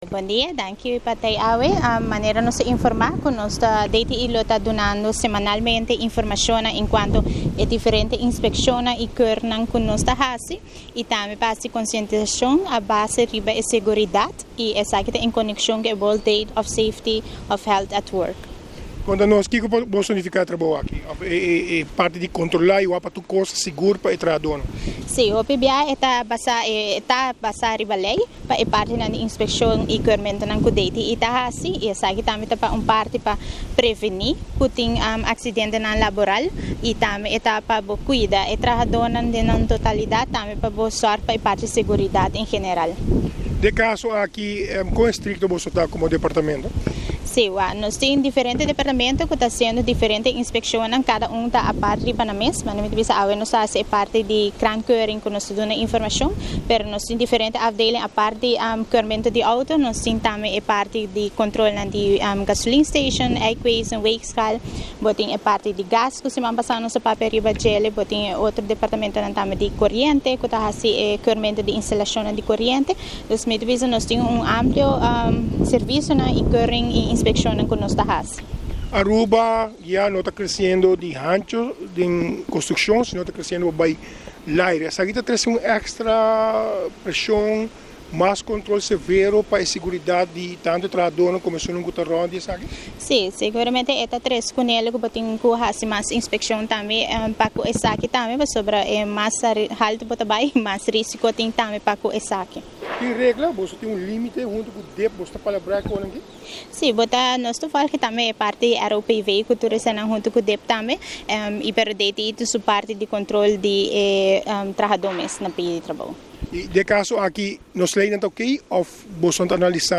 Interviews